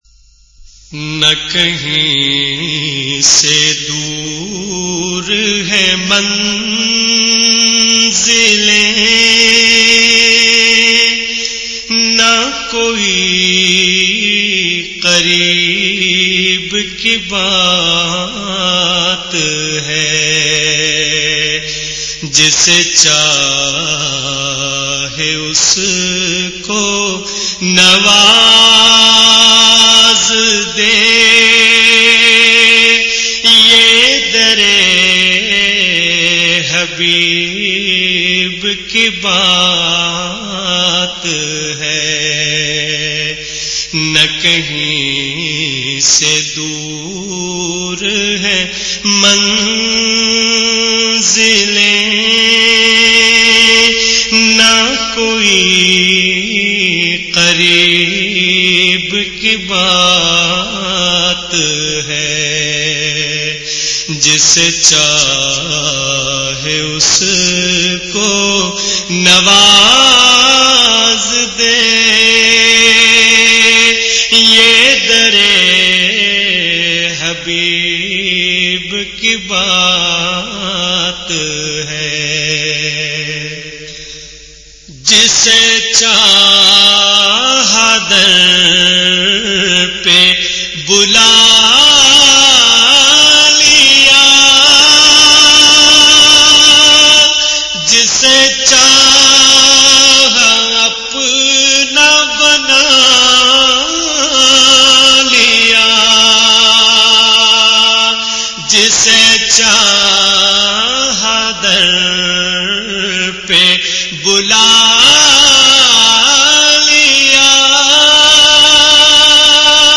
Beautifull voice
in best audio quality